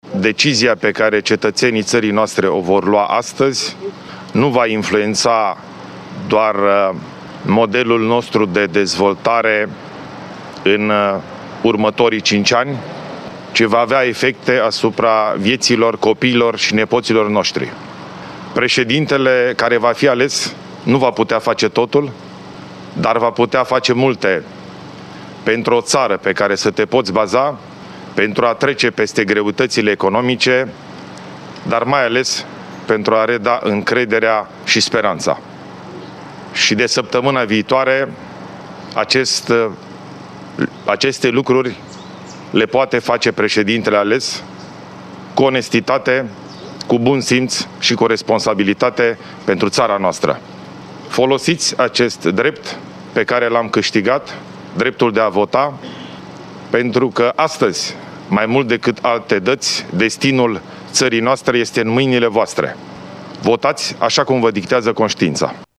Președintele României, Ilie Bolojan, a votat la ora 8,20 la Școala generală nr. 150 din Capitală. La ieșirea din secția de votare el a declarat presei că a votat conștient de importanța acestui vot: